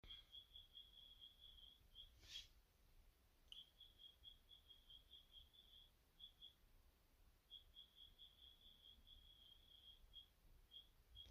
Petits cri dans la maison
Bonjour, Je viens d'emm�nager dans une maison et ce matin en me levant j'entends des bruits � travers la cloison.